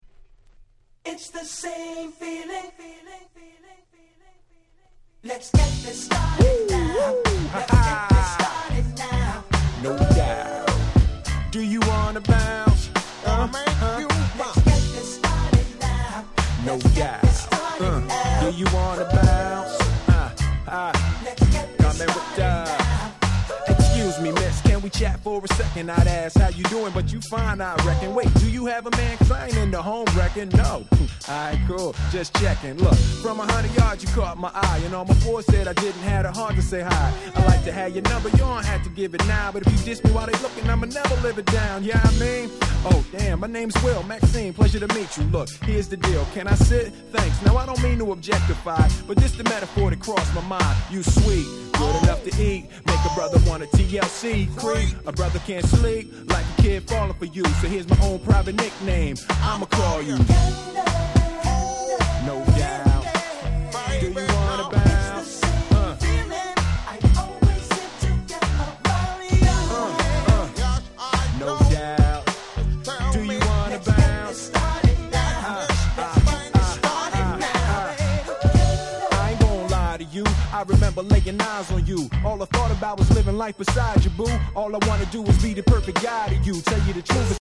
97' Super Hit Hip Hop LP !!